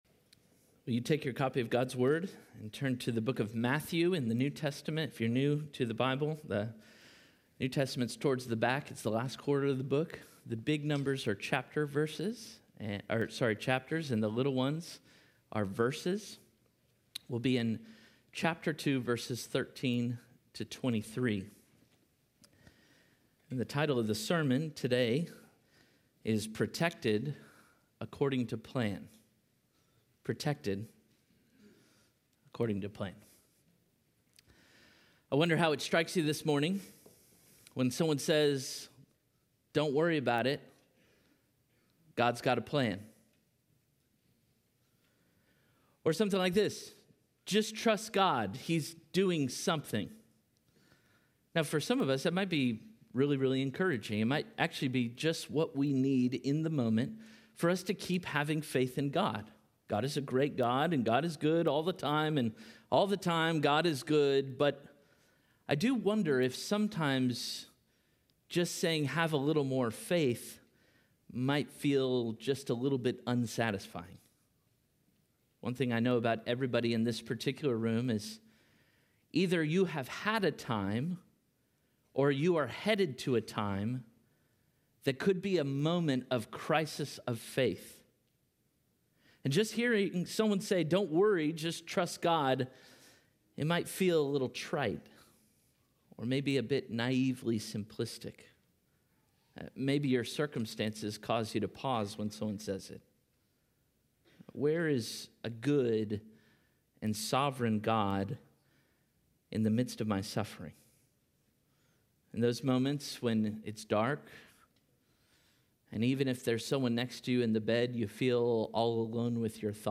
Parkway Sermons